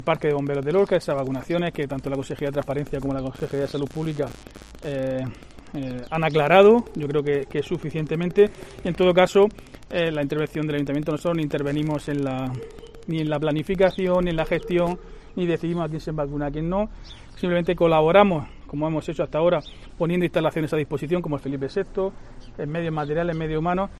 Diego José Mateos, alcalde de Lorca sobre vacunas bomberos